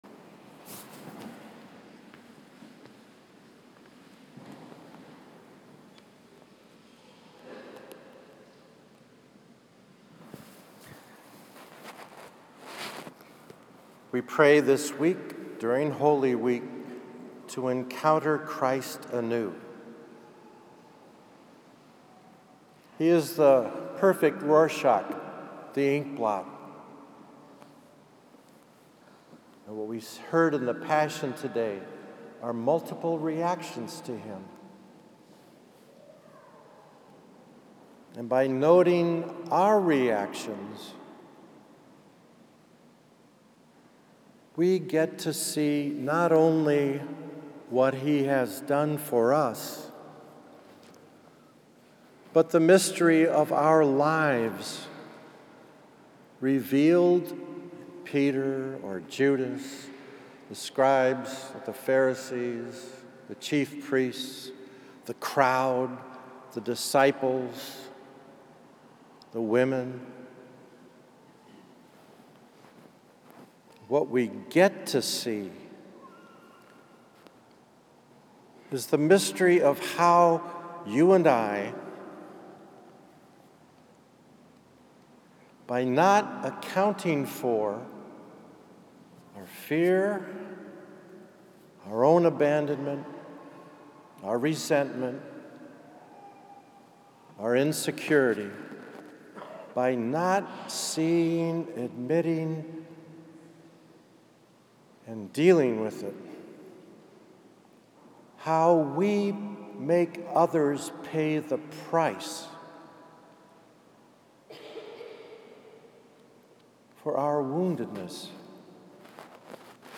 Palm Sunday 2017 – Homily audio